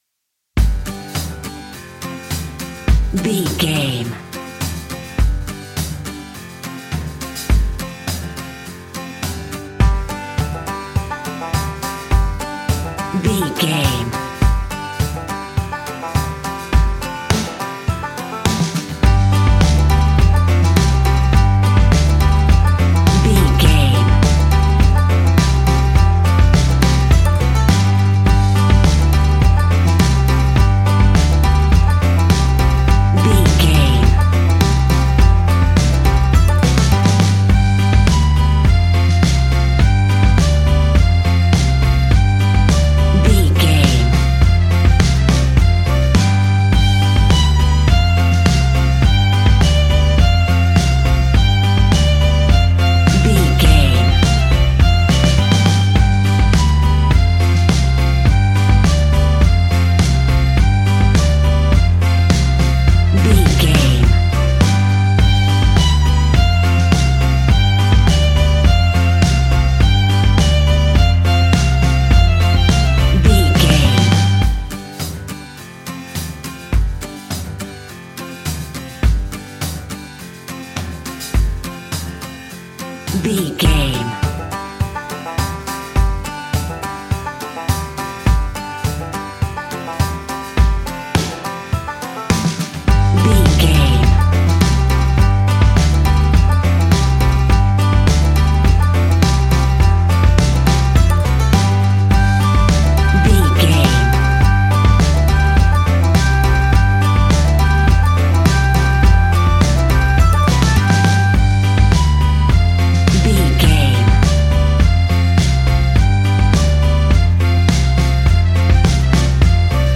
Uplifting
Ionian/Major
acoustic guitar
mandolin
ukulele
lapsteel
drums
double bass
accordion